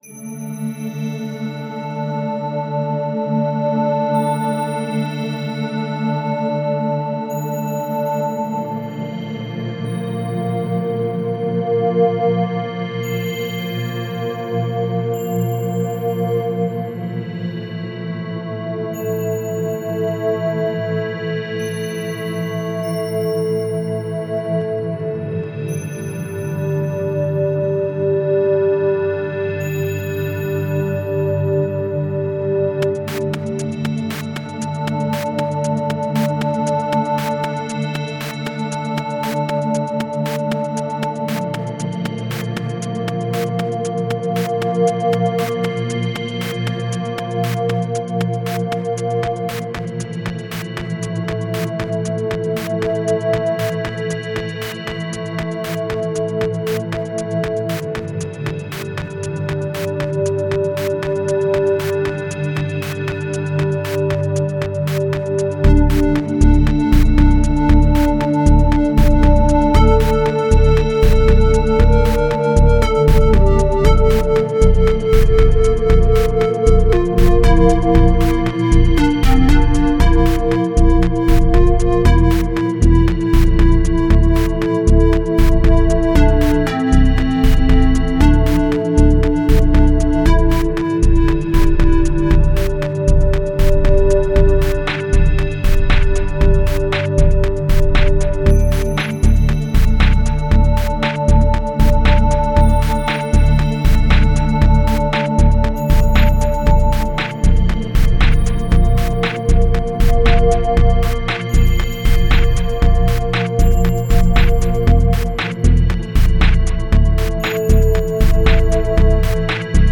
One of your great ambient things.
Groovy drums--match very well with the pad texture, though somehow I feel like you could put in a -tiny- bit more work into them. Perhaps a bit more variation and a light fill or two?